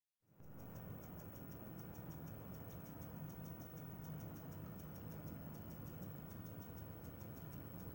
Jetzt gibt es nur noch ein deutlich leisere Geräusch das die Grafikkarte unter Last macht. Nicht unbedingt unter Volllast, sondern eher bei 40-60% Last.
Wenn man direkt mit dem Ohr zum Gehäuse geht (ca. 20cm entfernt) hört man es dann deutlicher, aber immernoch sehr leise.